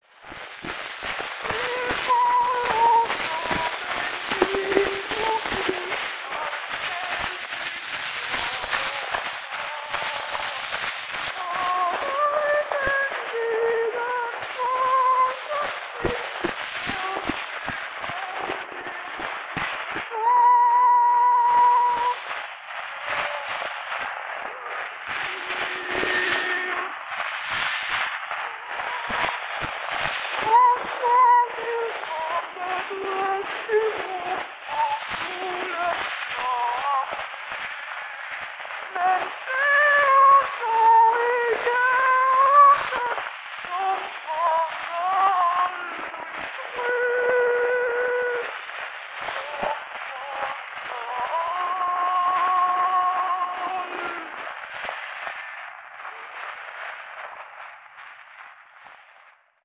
Komplet sang, som dog ødelægges af vanskeliggjort afspilning.
Rubenvalse